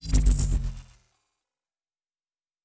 Shield Device 4 Stop.wav